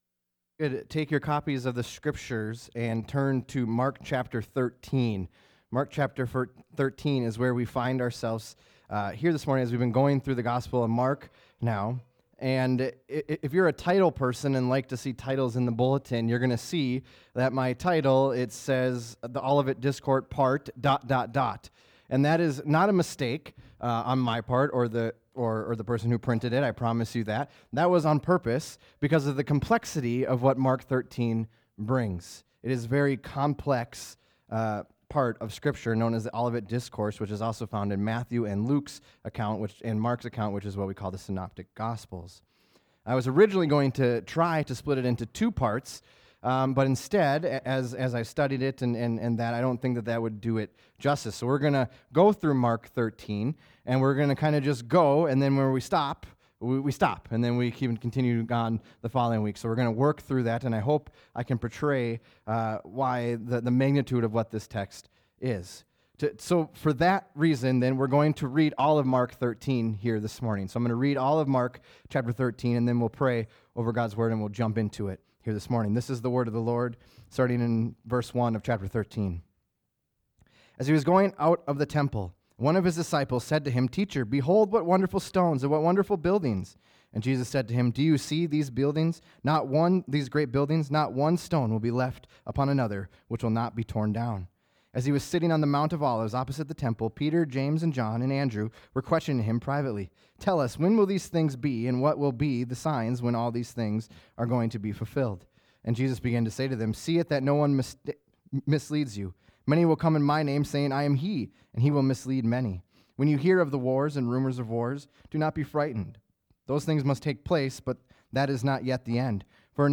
2024 9:30 am At about the 20 minute mark there is some static in the background for the audio.